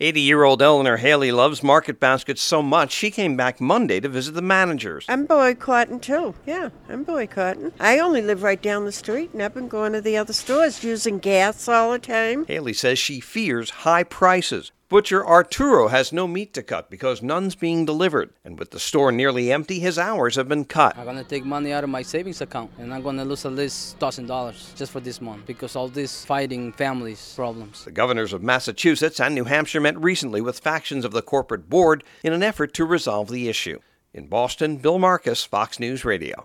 (SOMERVILLE, MA) AUG 19 – A CUSTOMER BOYCOTT AND WORKER STRIKE AGAINST A NEW ENGLAND GROCERY STORE CHAIN IS ENTERING ITS SECOND MONTH.